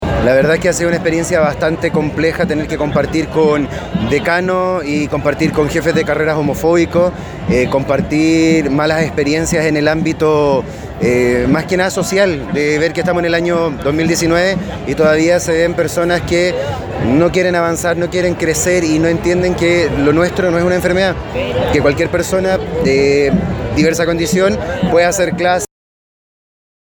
Desde la Izquierda Diario conversamos con varios docentes a lo largo de la marcha sobre lo que viven día a día en las salas de clase, siendo parte de la diversidad sexual.